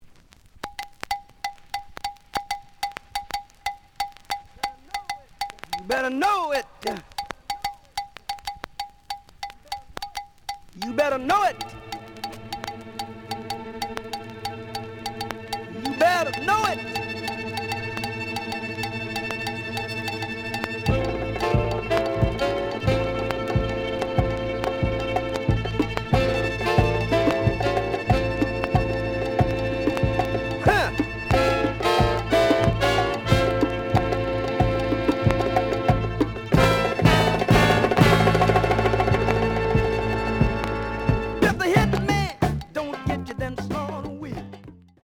The audio sample is recorded from the actual item.
●Genre: Funk, 70's Funk
Some click noise on B side due to scratches.